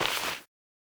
PixelPerfectionCE/assets/minecraft/sounds/item/hoe/till4.ogg at 0cc5b581cc6f975ae1bce078afd85fe00e0d032f
till4.ogg